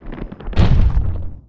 人死亡倒地zth070522.wav
WAV · 64 KB · 單聲道 (1ch)
通用动作/01人物/04人的声音/死亡/人死亡倒地zth070522.wav